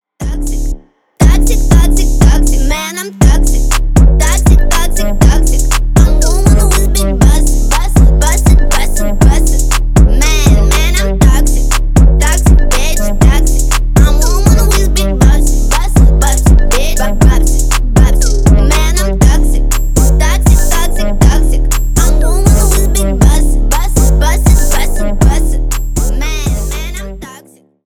рэп , хип хоп